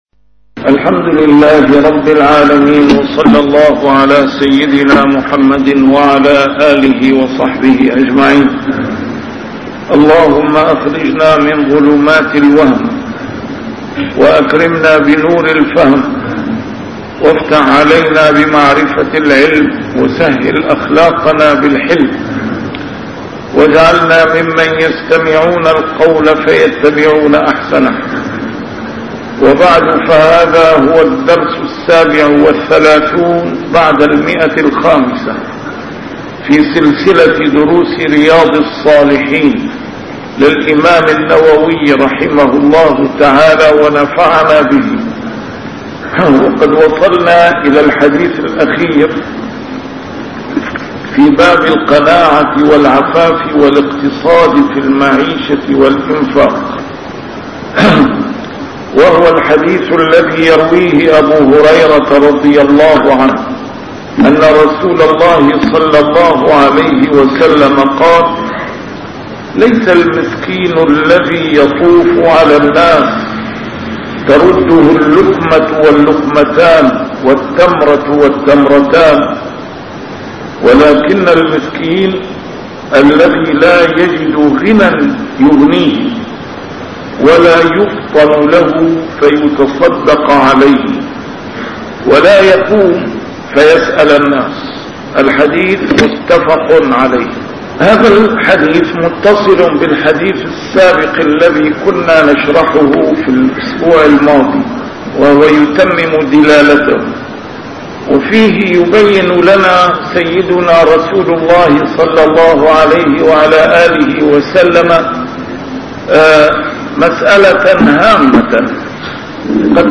A MARTYR SCHOLAR: IMAM MUHAMMAD SAEED RAMADAN AL-BOUTI - الدروس العلمية - شرح كتاب رياض الصالحين - 537- شرح رياض الصالحين: القناعة